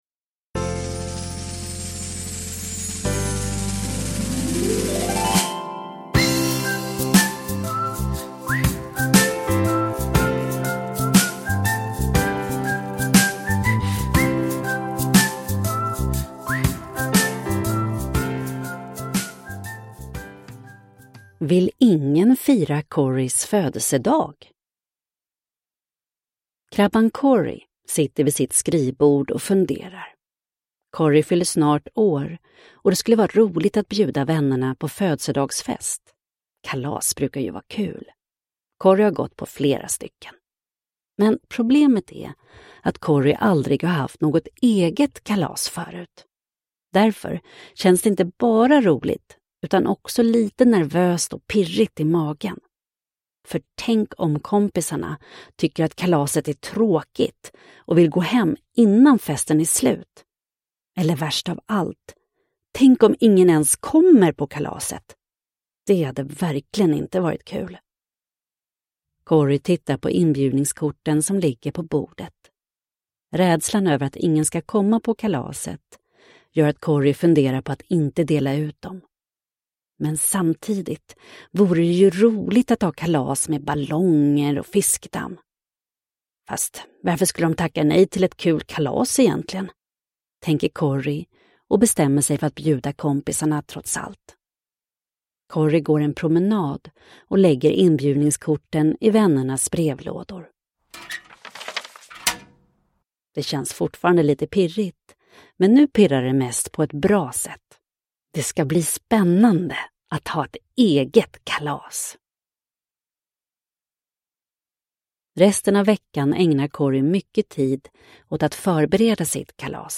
Vill ingen fira Corys födelsedag? – Ljudbok – Laddas ner